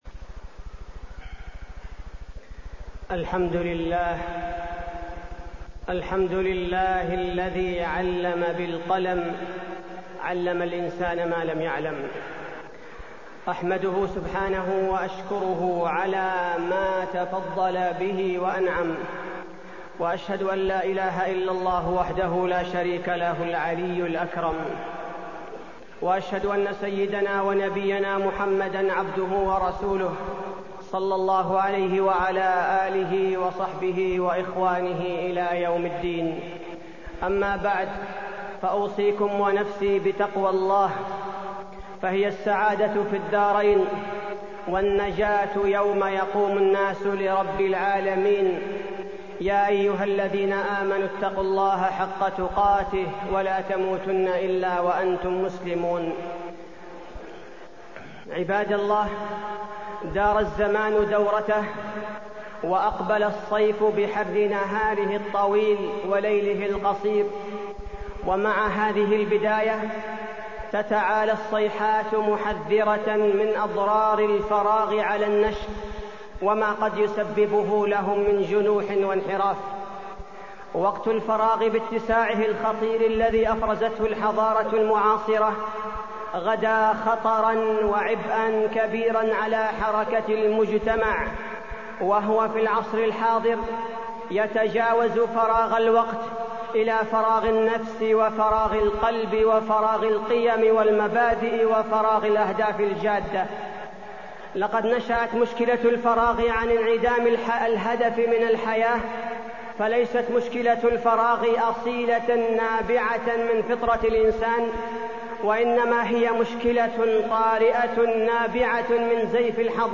تاريخ النشر ٢٦ ربيع الأول ١٤٢٣ هـ المكان: المسجد النبوي الشيخ: فضيلة الشيخ عبدالباري الثبيتي فضيلة الشيخ عبدالباري الثبيتي كيف نقضي الأجازة